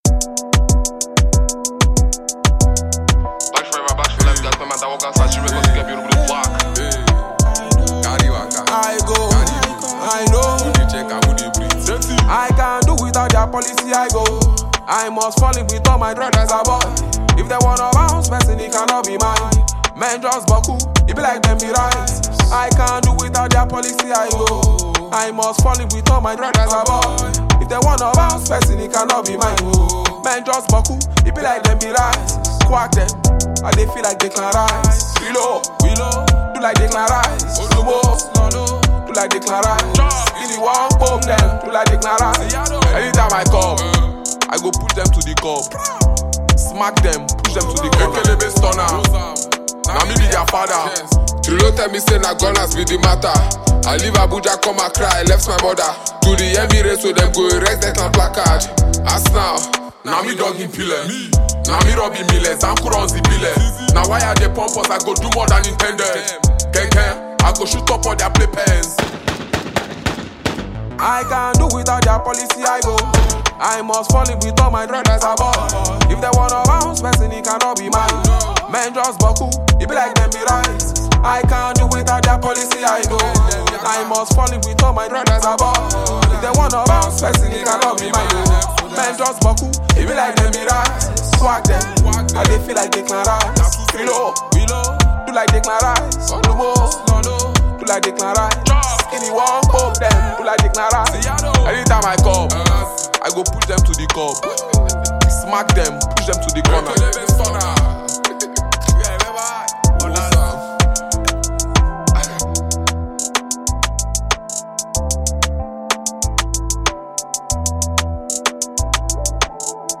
Phenomenon talented Nigerian rap artist and performer